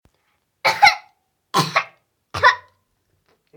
Tos infantil
Grabación sonora de tos infantil
Sonidos: Acciones humanas